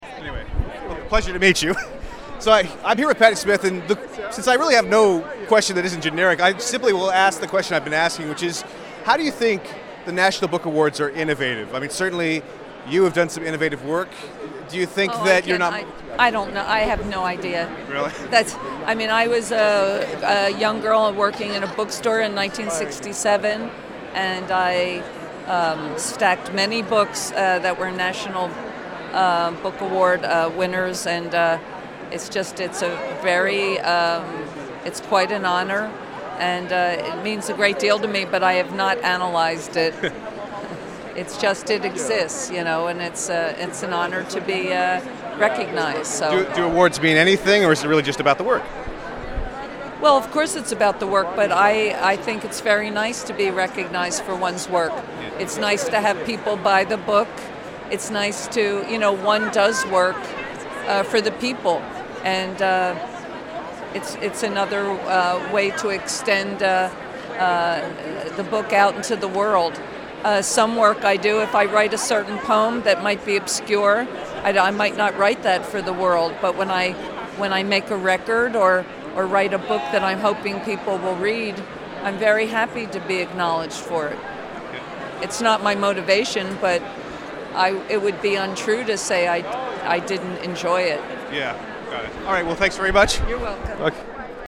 7:41 PM: Audio interview with Patti Smith.